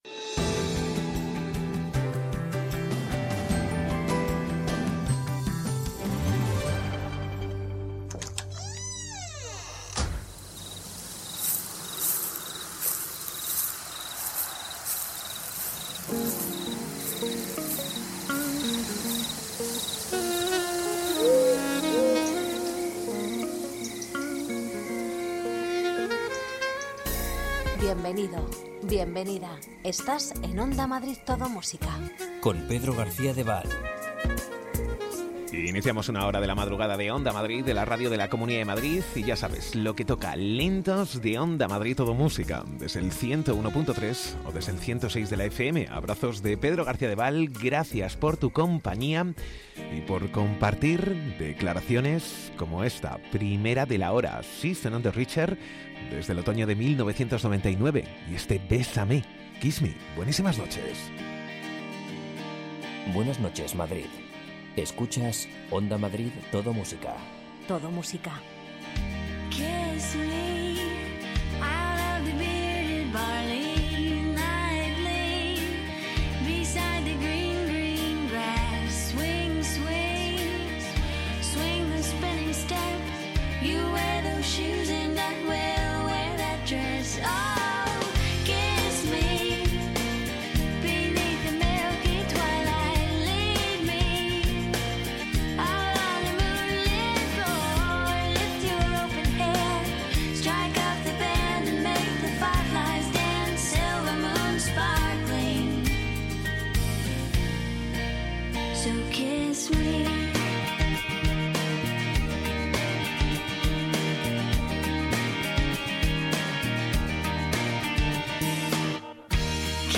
Los mejores lentos
Ritmo tranquilo, sosegado, sin prisas...